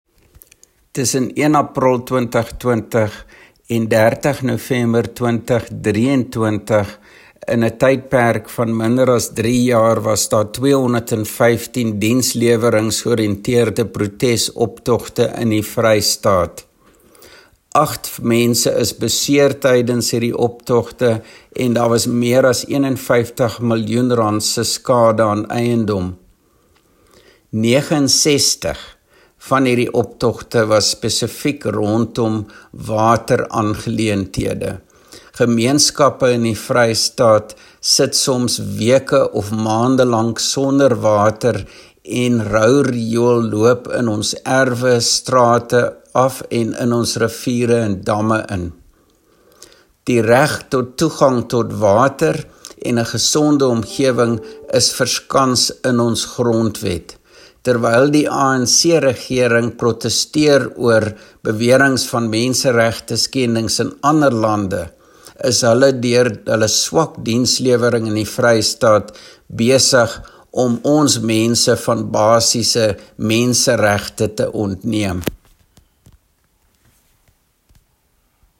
Afrikaans soundbites by Roy Jankielsohn MPL